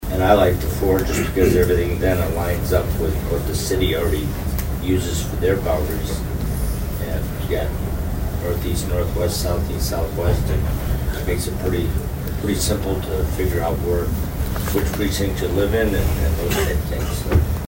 ABERDEEN, S.D.(HubCityRadio)- At Tuesday’s Brown County Commission meeting, the commissioners received a report from Brown County Auditor Lyn Heupel talking about the number of precincts within the city of Aberdeen.